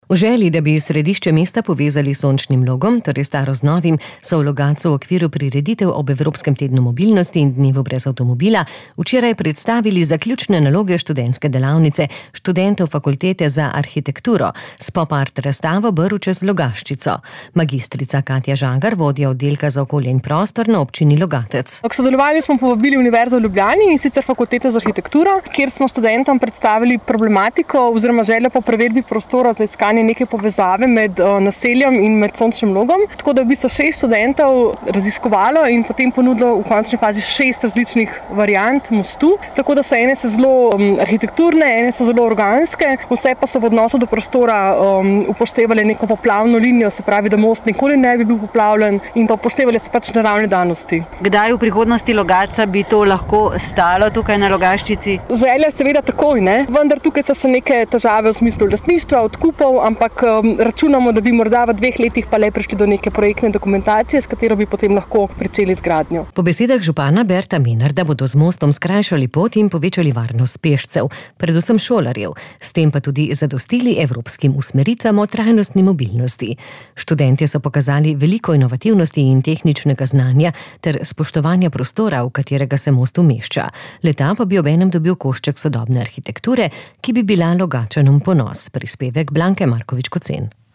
Pogovarjali smo se z mag.